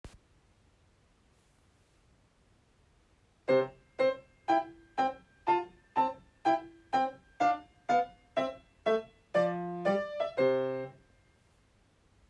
音源②：ソフトペダルを踏んだヴァージョン
わずかかもしれませんが、ソフトペダルを踏んだ音源②の方がこもったような、やわらかい音色になっているのが分かりますでしょうか？